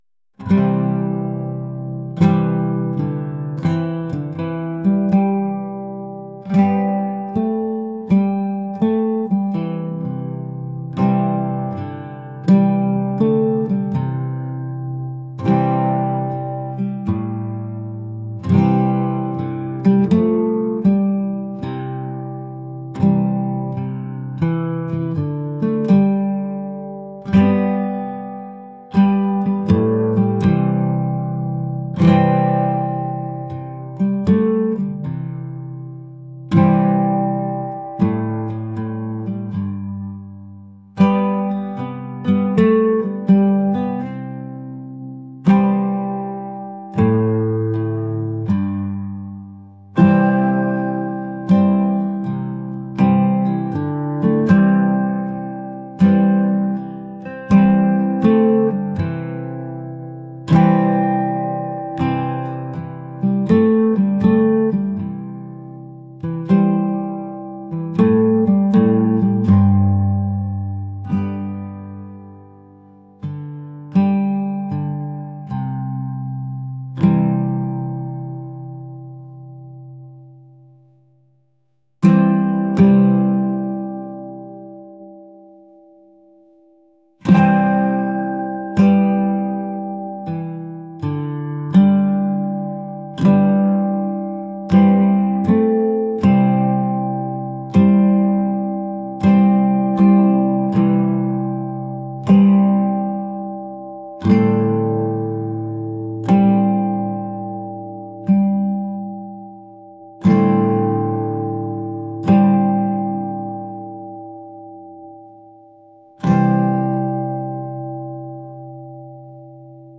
indie | folk | traditional